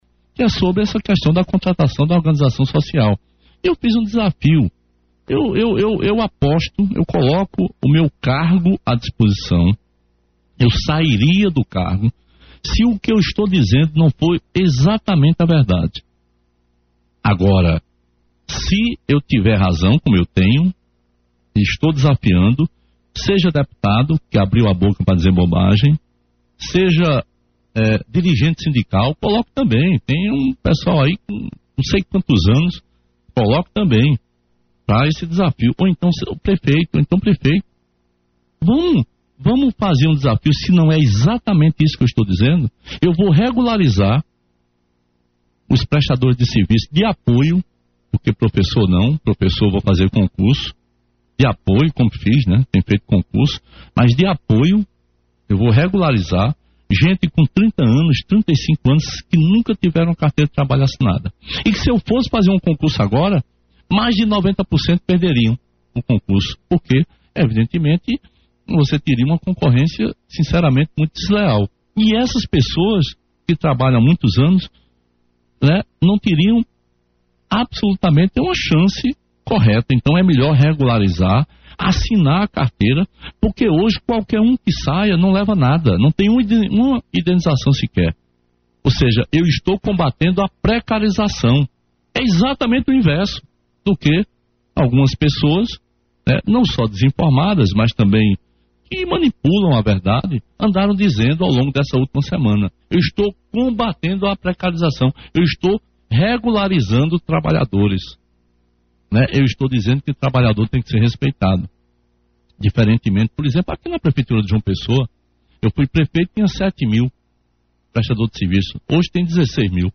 Ouça o governador Ricardo Coutinho hoje no programa Fala Governador.